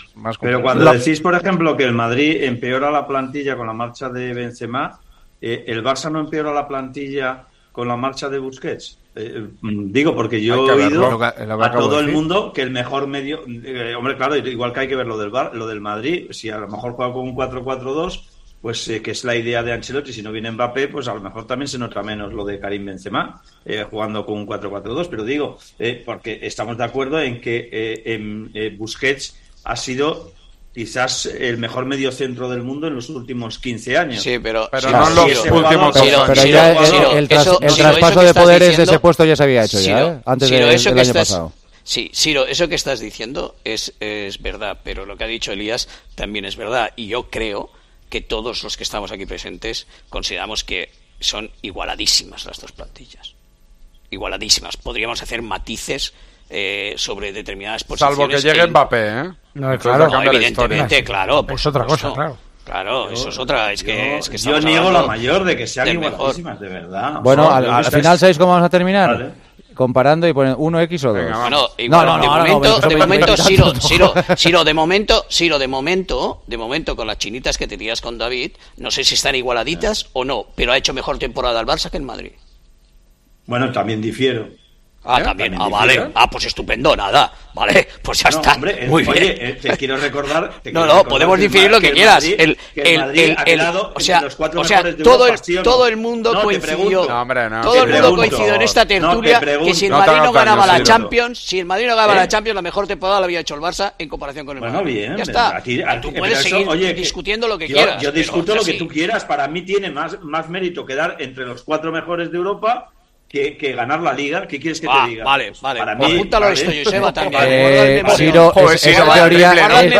El Partidazo de COPE debate sobre el papel que deben hacer Barcelona y Real Madrid en la Champions
AUDIO: Los tertulianos de El Partidazo de COPE valoran las palabras de Joan Laporta sobre la superioridad de la plantilla del Barcelona.